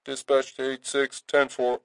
描述：Dispatch line two in dialog
标签： Chatter Police Radio
声道立体声